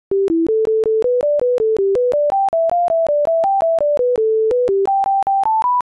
Bonny Dundee, giga dance form (1751), first 4 measures
Hair thickness is proportional to the number of different patterns in a tune, and these tunes have, relatively speaking, a small number of different patterns, largely because they lack tied notes and large intervals, features common to many folk tunes.
Bonny-Dundee-giga-1751-OPC3.m4a